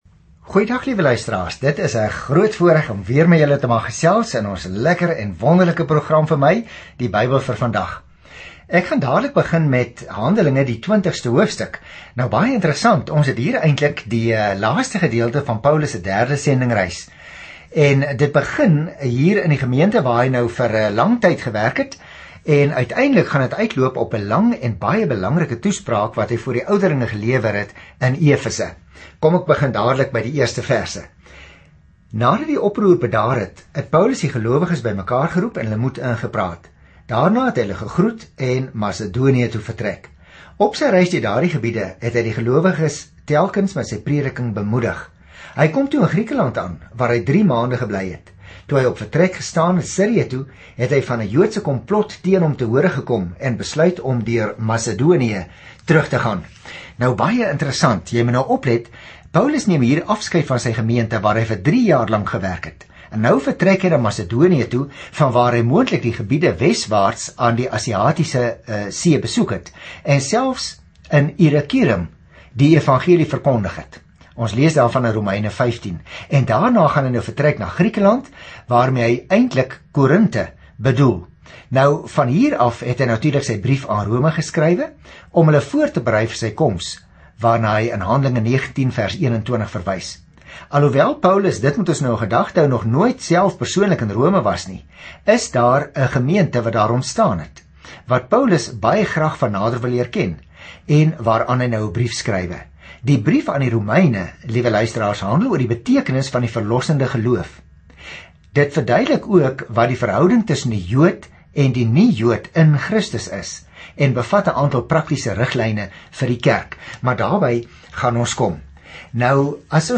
Skrif HANDELINGE 20 Dag 29 Begin met hierdie leesplan Dag 31 Aangaande hierdie leesplan Jesus se werk begin in die Evangelies gaan nou voort deur sy Gees, soos die kerk geplant word en groei oor die hele wêreld. Reis daagliks deur Handelinge terwyl jy na die oudiostudie luister en uitgesoekte verse uit God se woord lees.